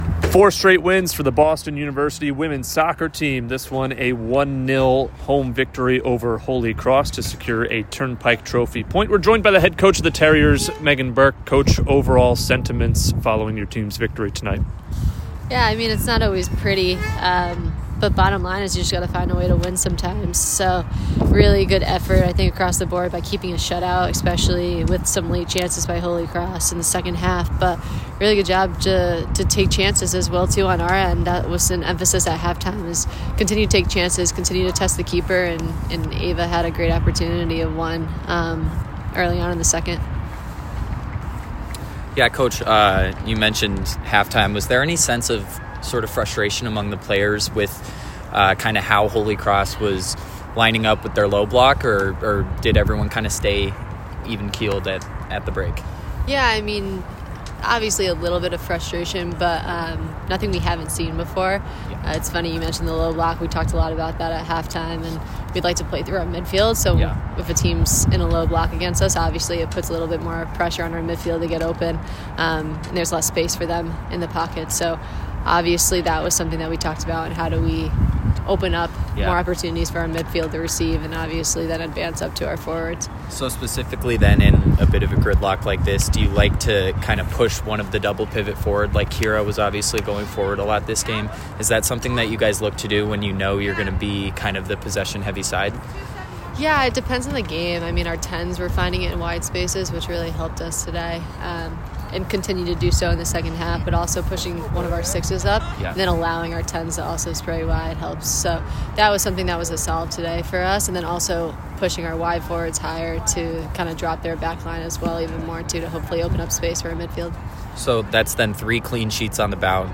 Women's Soccer / Holy Cross Postgame Interviews (10-15-25) - Boston University Athletics